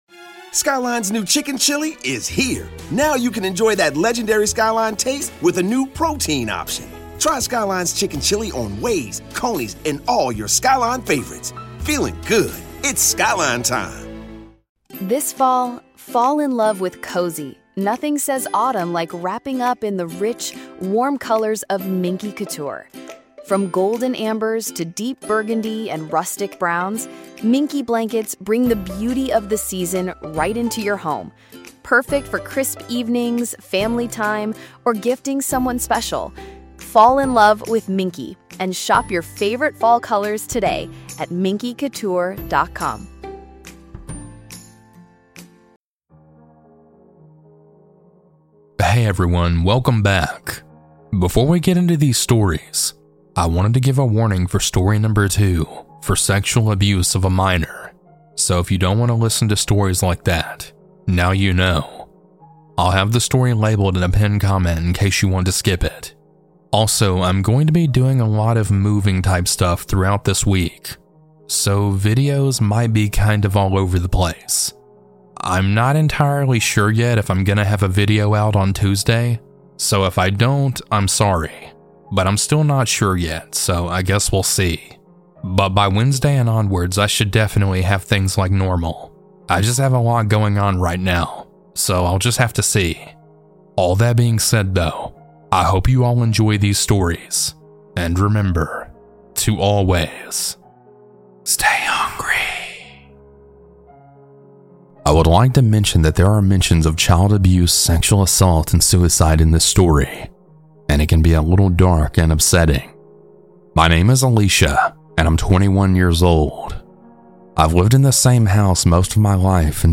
- Anonymous Huge Thanks to these talented folks for their creepy music!